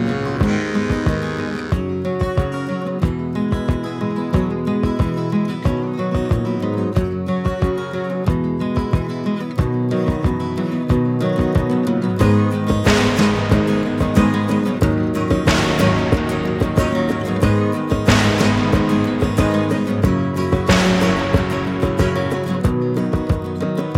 With Backing Vocals Pop (1960s) 5:10 Buy £1.50